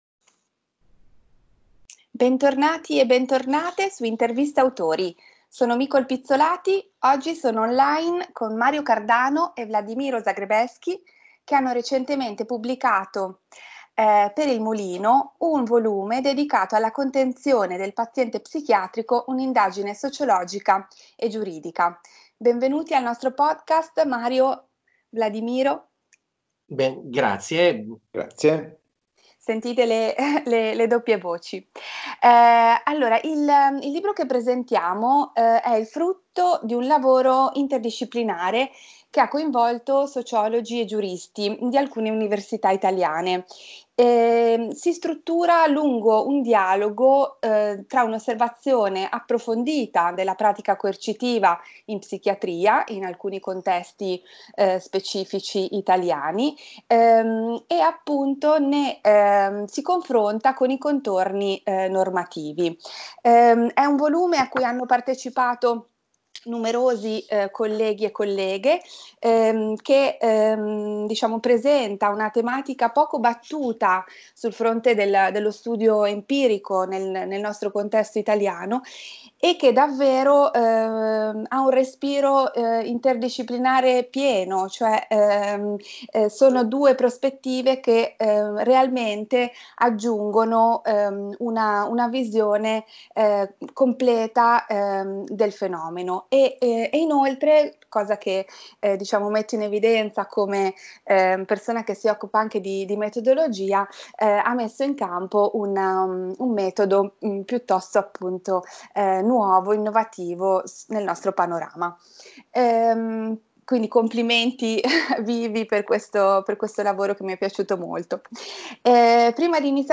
che si riflettono in questa intervista a tre voci.